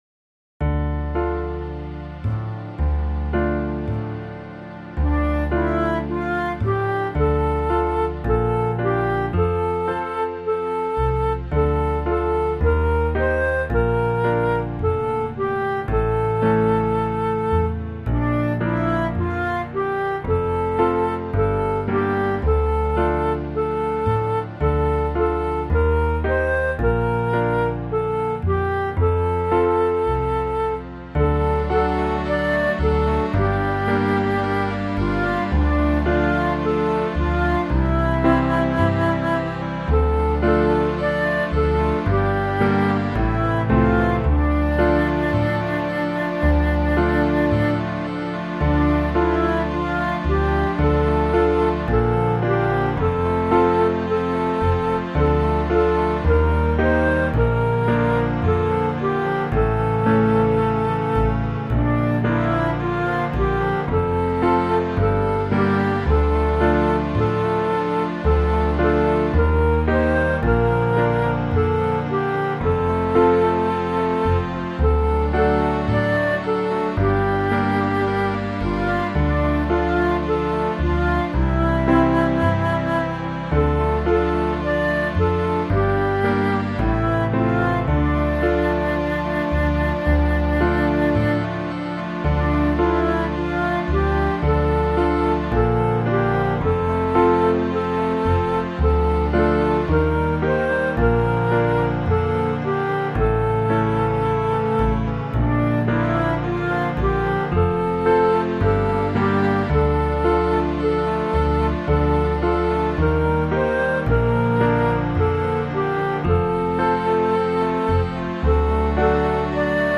Let All Mortal Flesh Keep Silence | Church Music
Mary’s parish sing this song suitable for Advent, with a text translated from a fourth century liturgy, so that’s going way back.